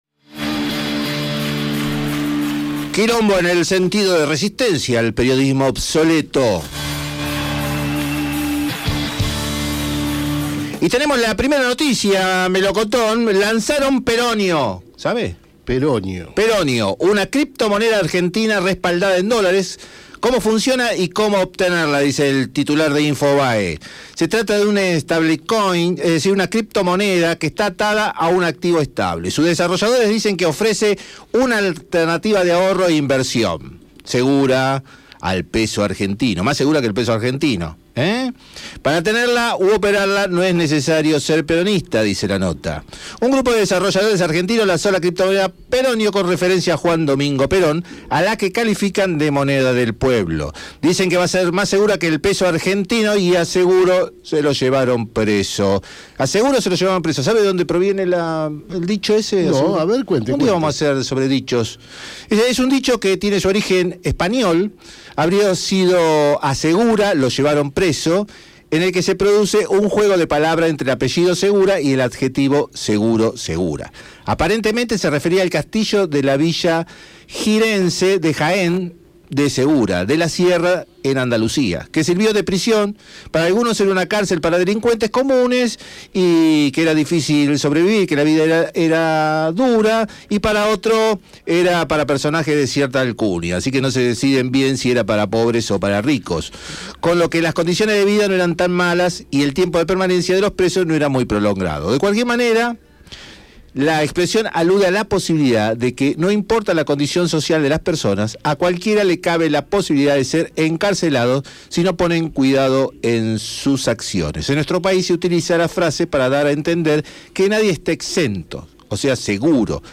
Compartimos el cuarto resumen de las noticias de Mercedes y del mundo